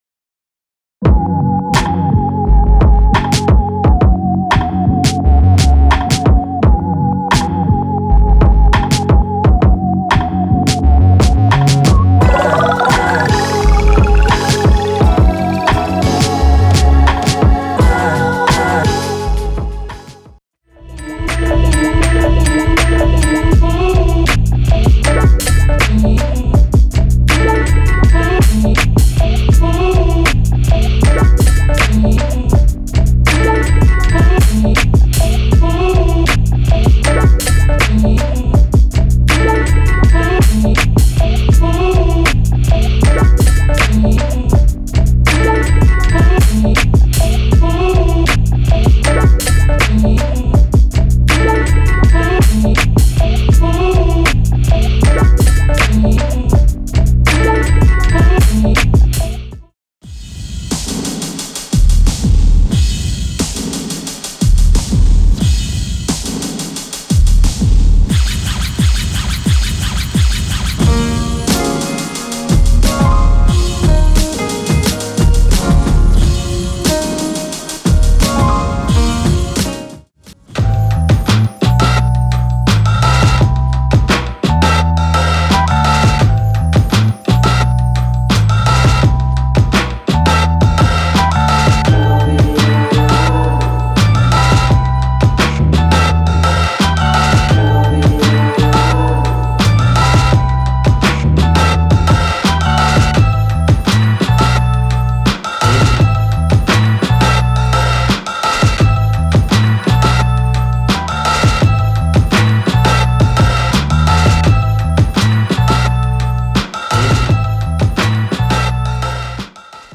ダイジェストになります。
東京生まれ、HIP HOPのビートメーカー。